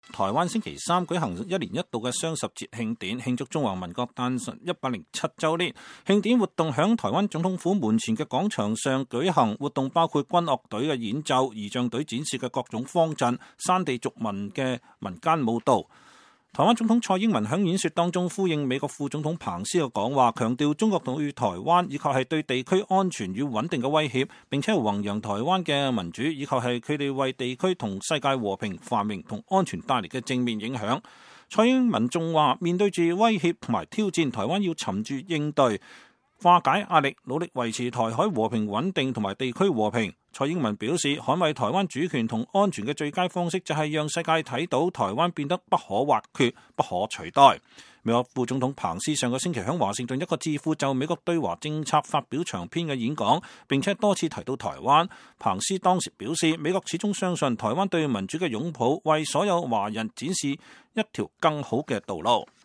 台灣星期三（2018年10月10日）舉行一年一度的雙十節慶典，慶祝中華民國誕辰107週年。慶典活動在台灣總統府門前的廣場上舉行。活動包括軍樂隊的演奏，儀仗隊展示的各種方陣，山地族民的民間舞蹈。